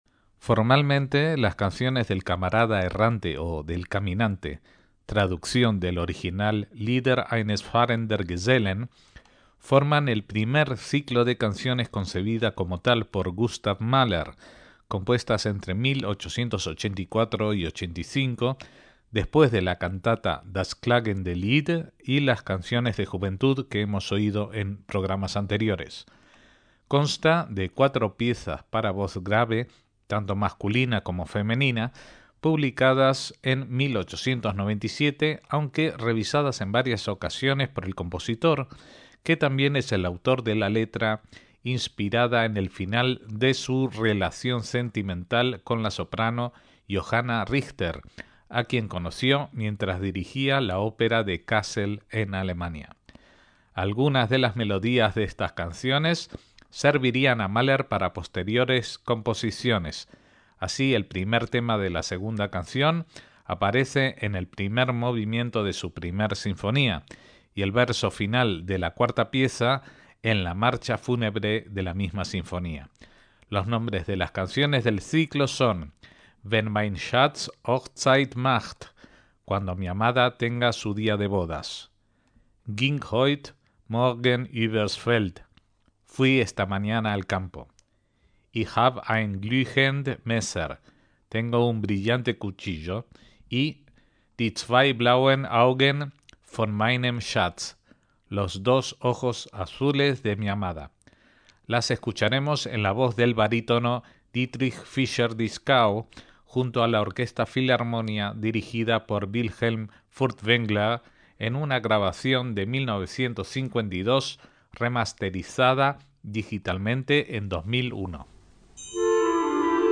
Son interpretadas con piano u orquesta generalmente por un barítono o una mezzosoprano.